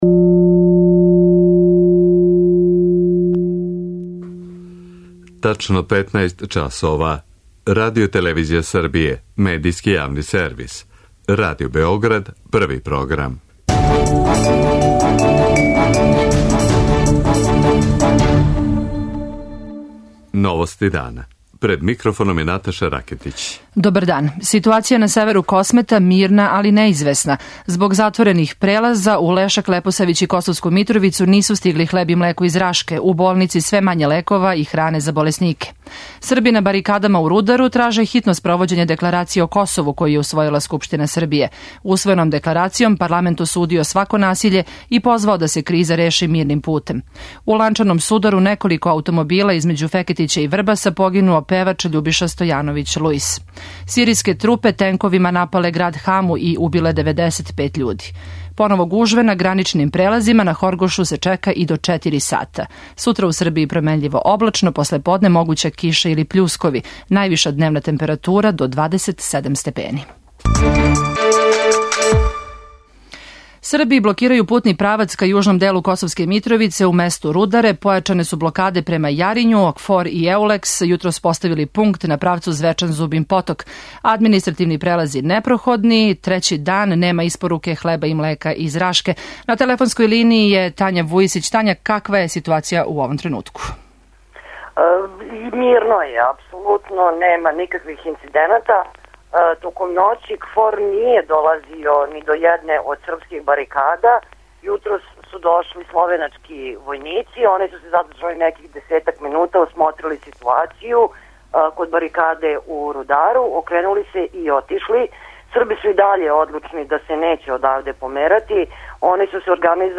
О ситуацији на северу Косова и Метохије извештавају наши репортери.